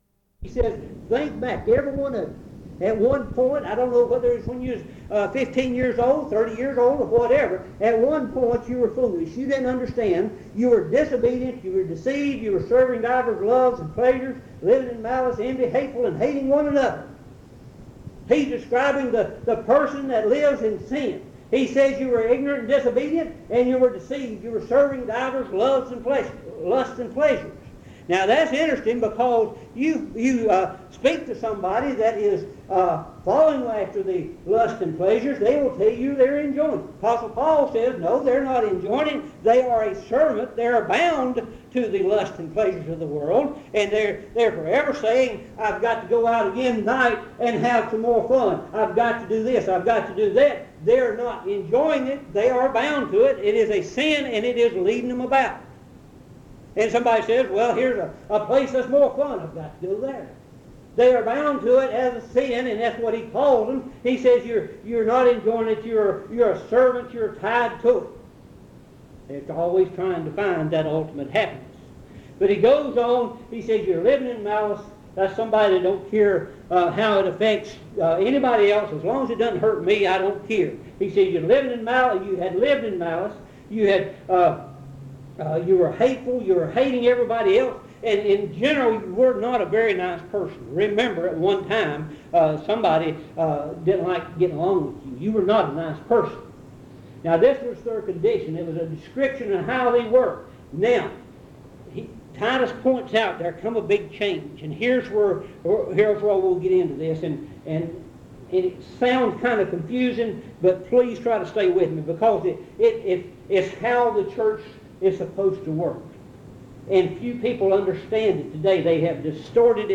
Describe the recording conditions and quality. at Monticello Primitive Baptist Church